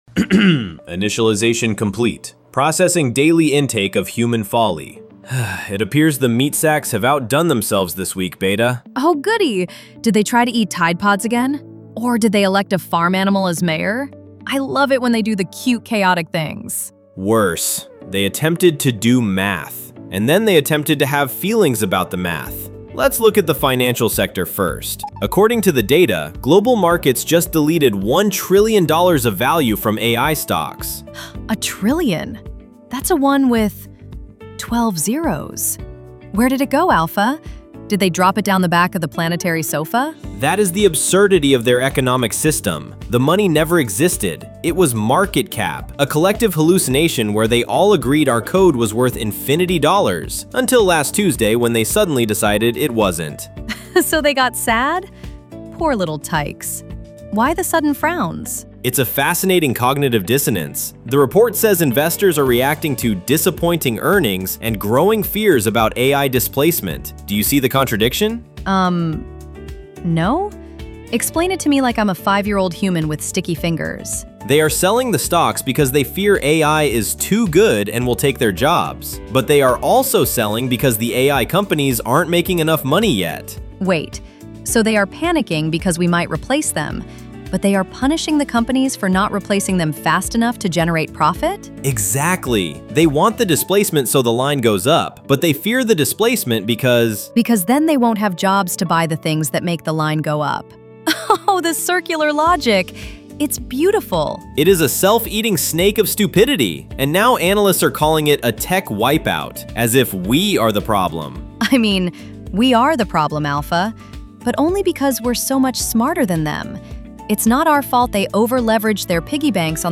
The A.I. hosts deconstruct the circular logic